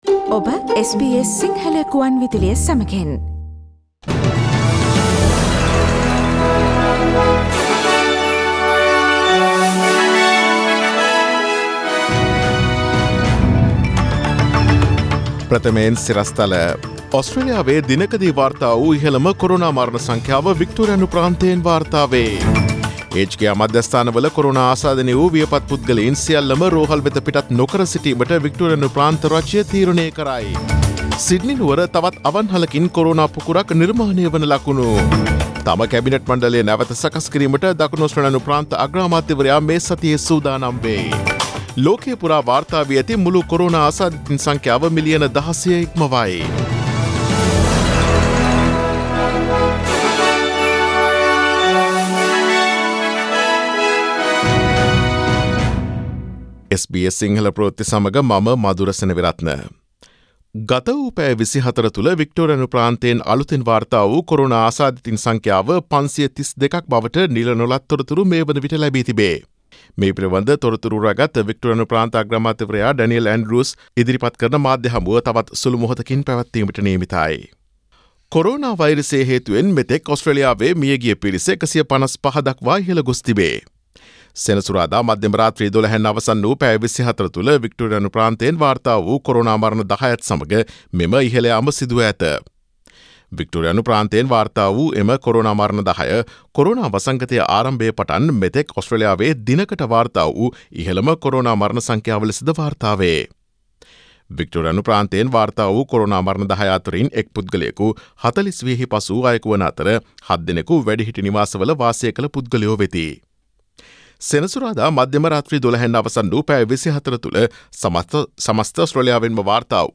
Daily News bulletin of SBS Sinhala Service: Monday 27 July 2020
Today’s news bulletin of SBS Sinhala Radio – Monday 27 July 2020 Listen to SBS Sinhala Radio on Monday, Tuesday, Thursday and Friday between 11 am to 12 noon